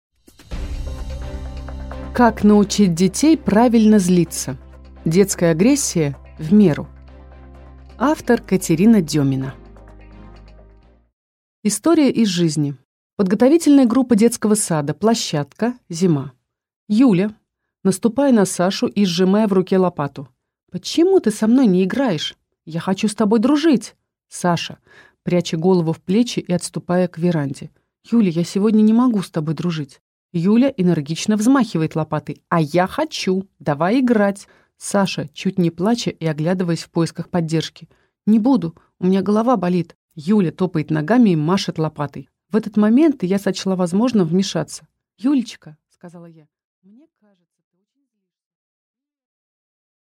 Аудиокнига Как научить детей правильно злиться | Библиотека аудиокниг
Прослушать и бесплатно скачать фрагмент аудиокниги